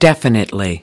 27 definitely (adv) /ˈdefɪnətli/ Minh bạch/ rạch ròi/ xác định